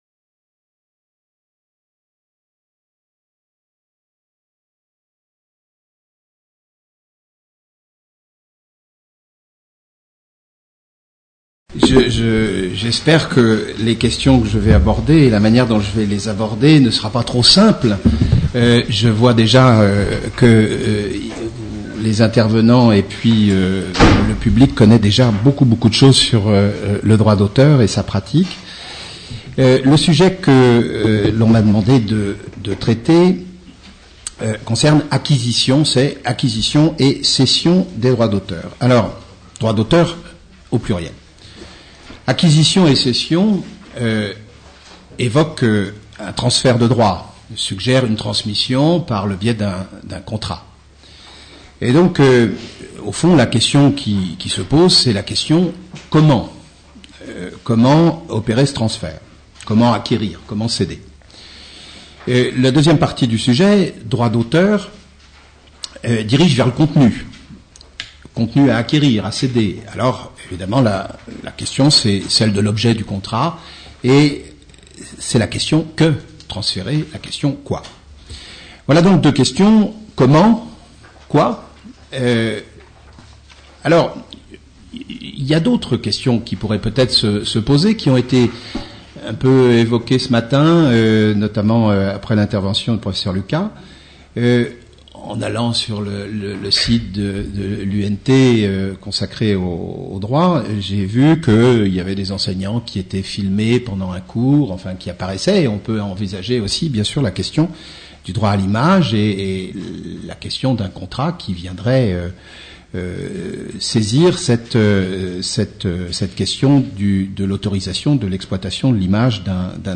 Questions de la salle et réponses des intervenants